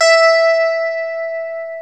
Index of /90_sSampleCDs/Club-50 - Foundations Roland/GTR_xStratChorus/GTR_xStratChorus
GTR XSTRAT0R.wav